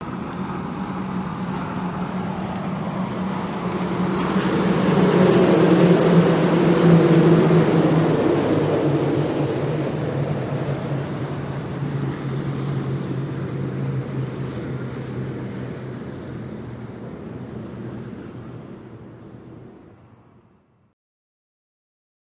Monorail, Disneyland, Long Smooth By